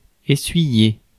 Ääntäminen
US RP : IPA : /mɒp/ GenAm: IPA : /mɑp/